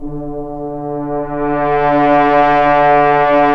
Index of /90_sSampleCDs/Roland L-CD702/VOL-2/BRS_Accent-Swell/BRS_FHns Swells